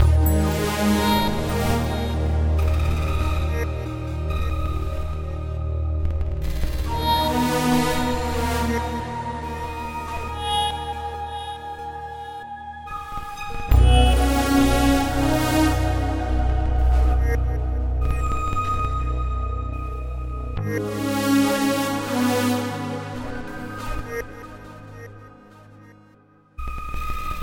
Tag: 140 bpm Trap Loops Synth Loops 4.61 MB wav Key : D